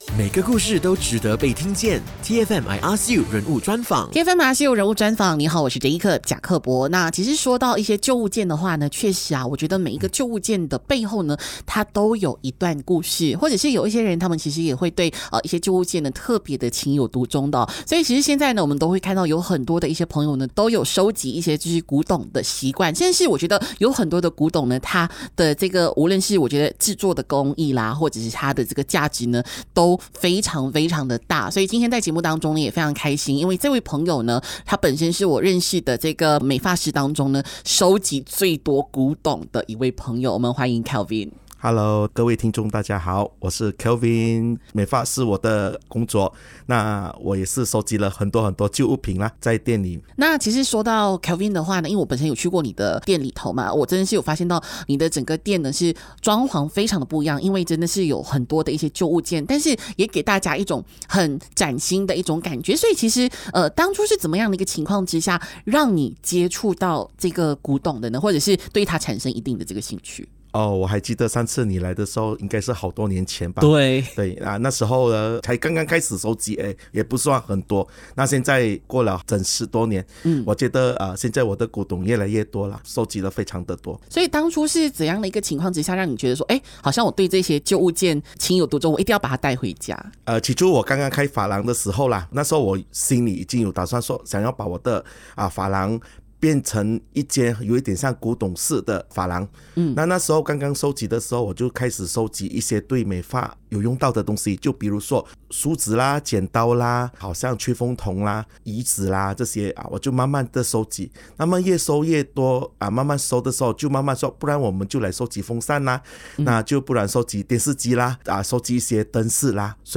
人物专访